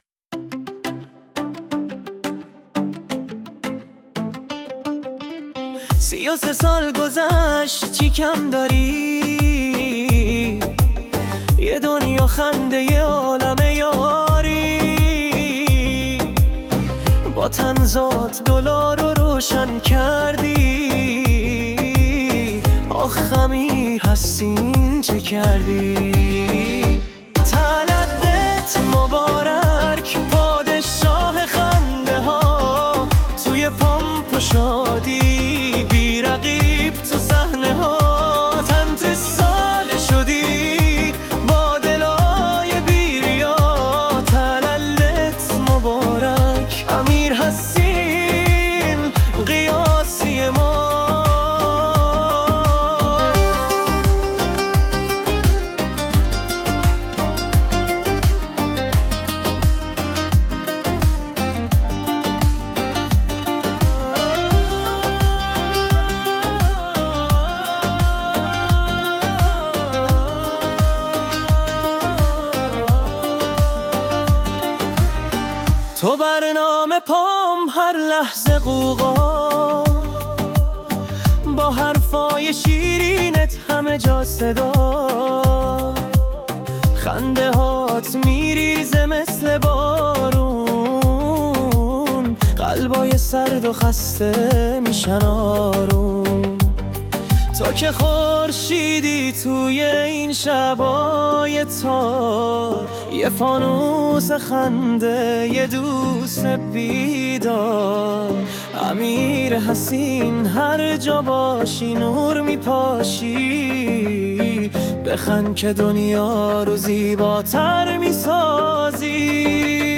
آهنک تولد امیرحسین قیاسی ساخته هوش مصنوعی را گوش دهید: